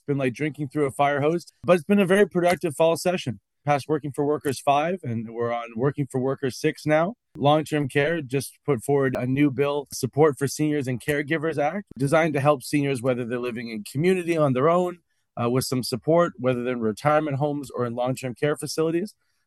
We spoke with Allsopp about his role and hitting the ground running.